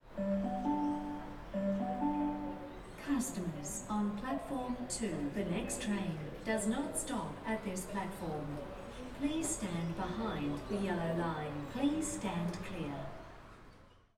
announcement.ogg